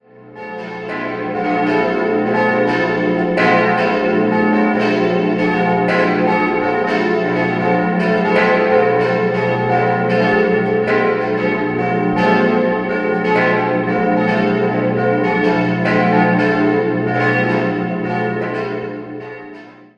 4-stimmiges Geläut: c'(-)-e'(+)-fis'-a' Die große Glocke stammt von Anton Gugg (Straubing) aus dem Jahr 1955. Die nächstgrößere Glocke hing ursprünglich aus dem Kloster Niedernburg (Passau) und wurde 1779 von Peter Anton Jacomini in Passau gegossen.